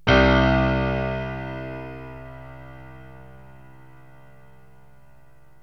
PIANO 0002.wav